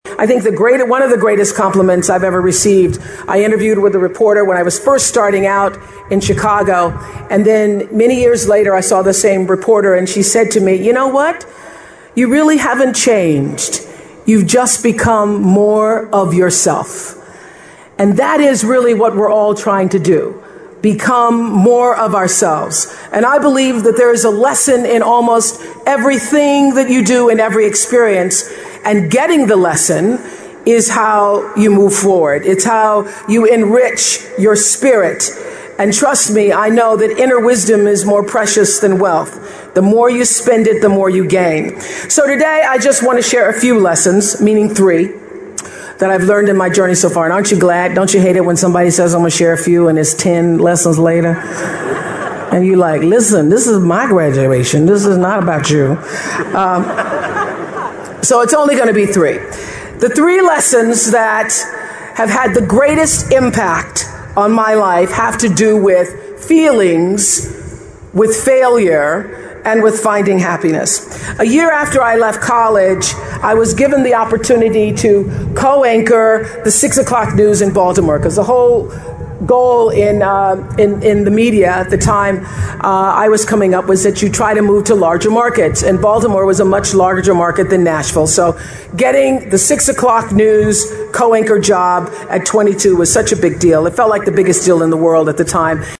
名人励志英语演讲 第143期:感觉失败及寻找幸福(5) 听力文件下载—在线英语听力室